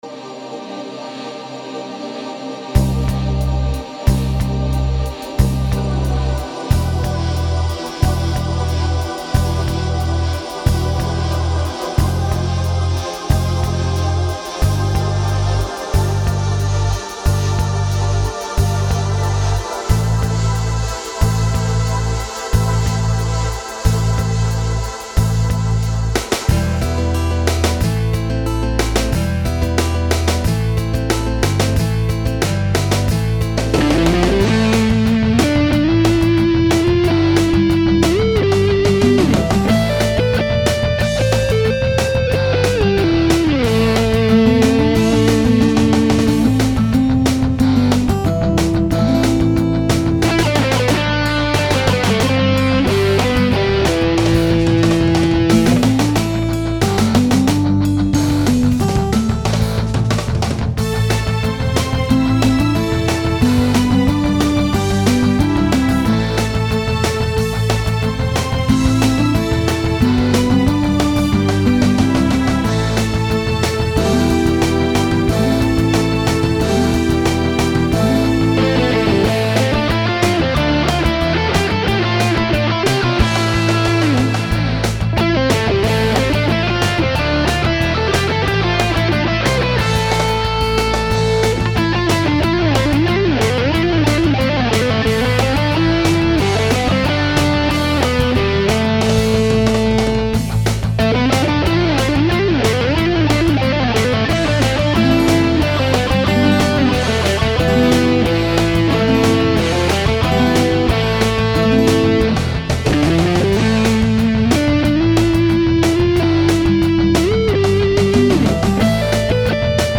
Ηλεκτρική Κιθάρα
Ακουστική Κιθάρα